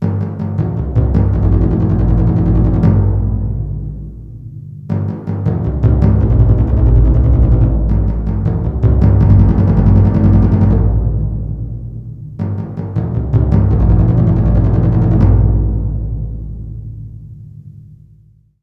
Timbales. Breve pieza.
membranófono
timbal
percusión